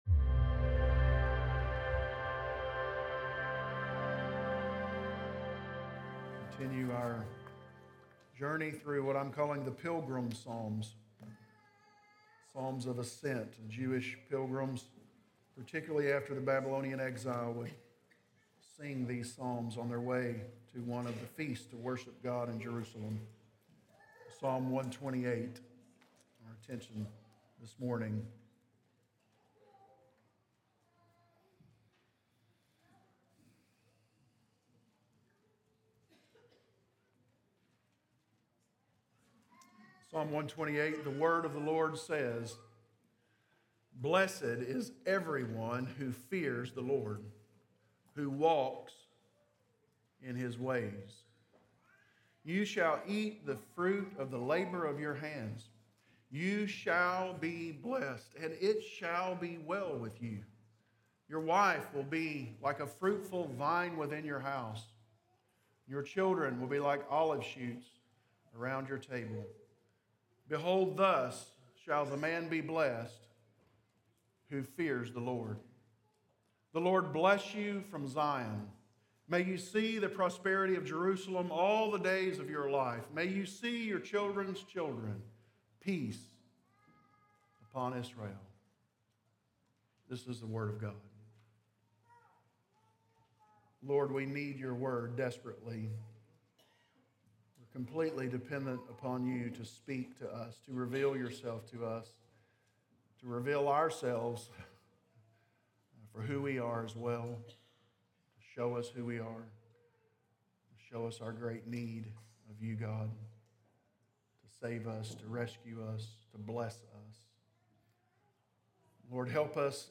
Sermonseries items dated: April, 2025